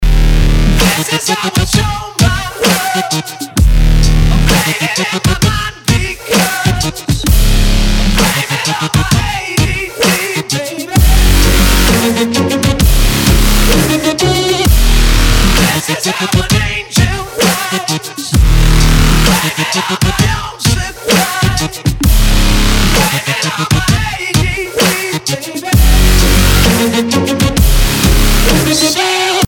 громкие
Bass